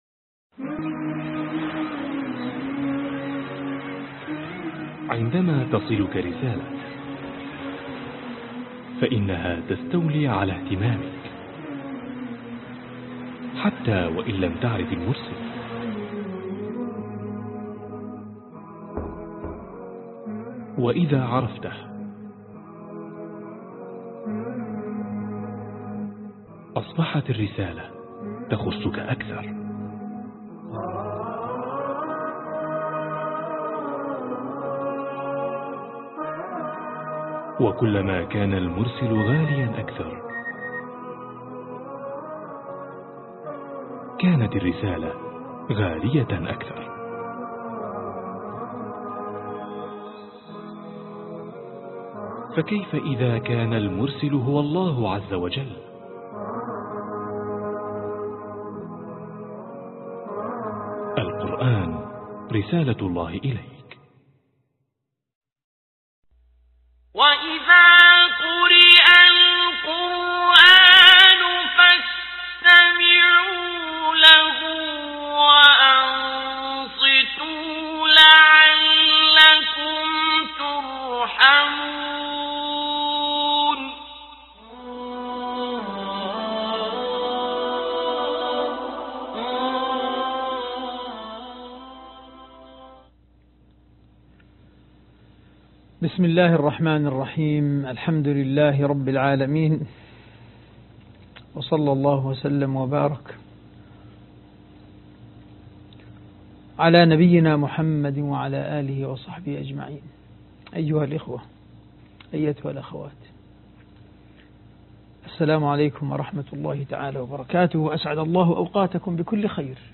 الدرس 10 ( البقرة ) لعلكم ترحمون